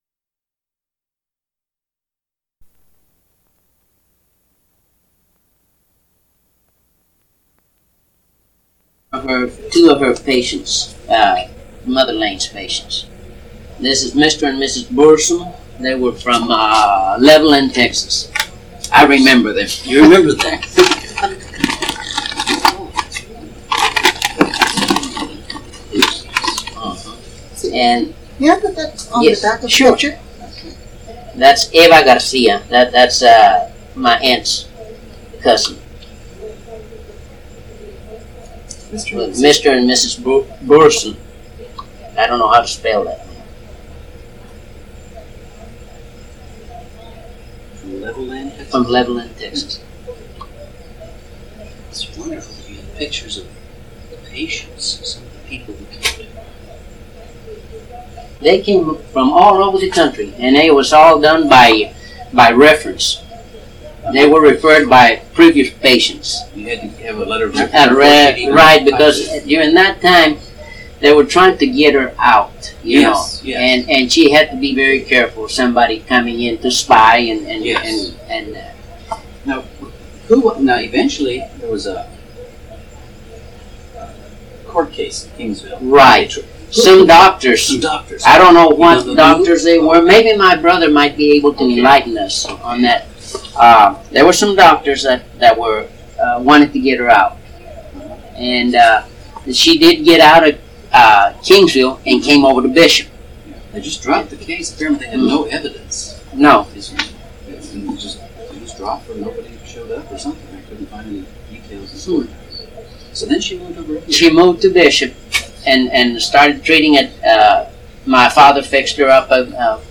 Oral History about a 1930s curandera
Interview